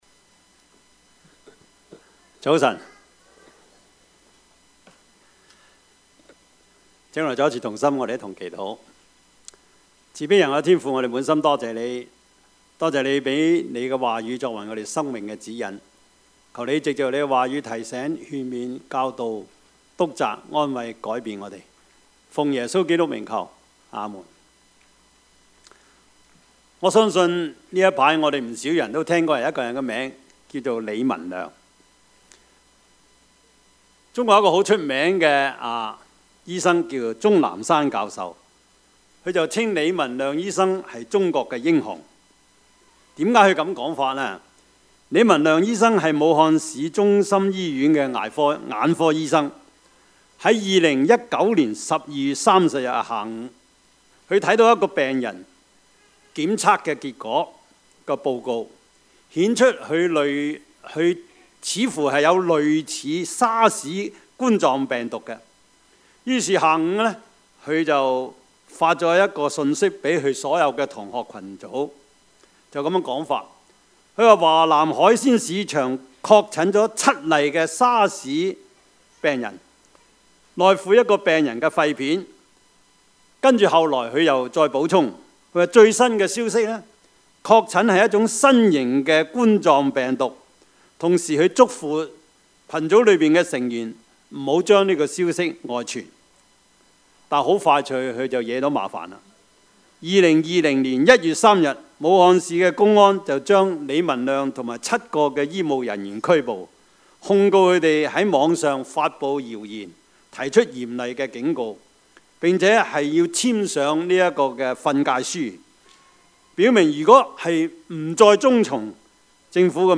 Service Type: 主日崇拜
Topics: 主日證道 « 好弟兄 美麗島事件 »